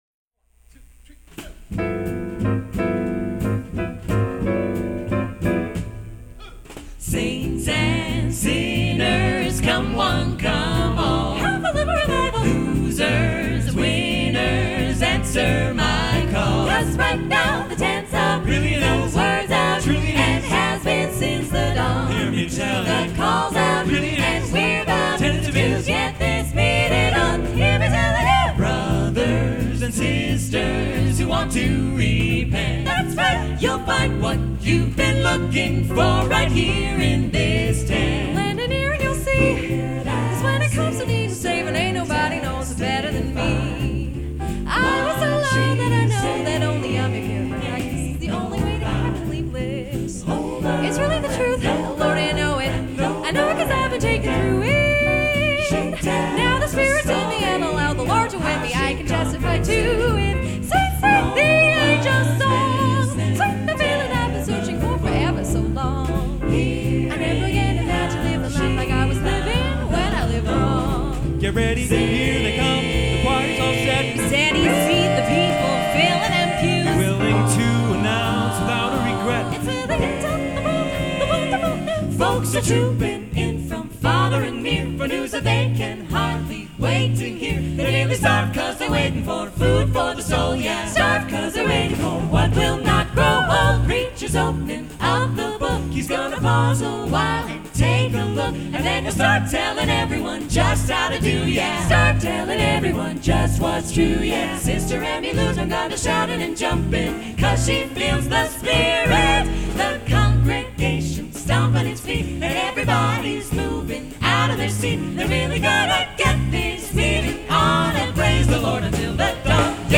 Live, Banff, 2004